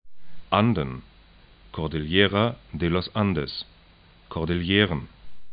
'andən
kɔrdɪl'je:rən